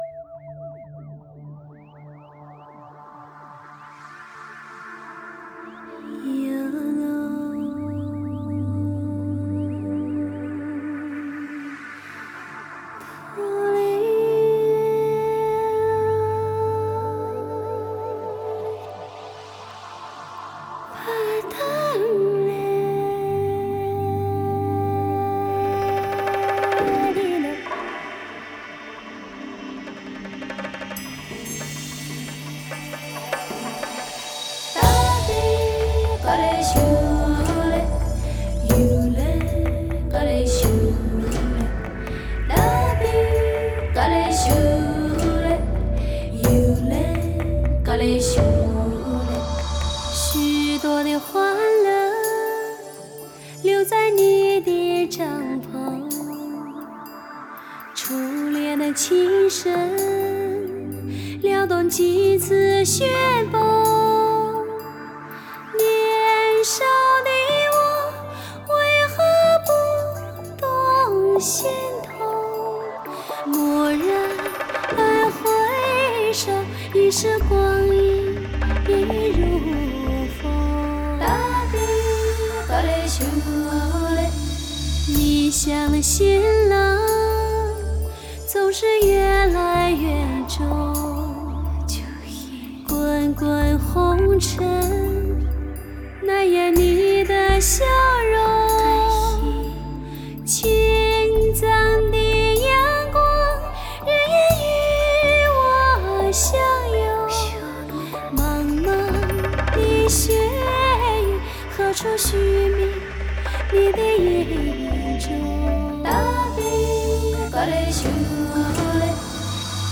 Жанр: Modern Traditional / Cinese pop / Miao folk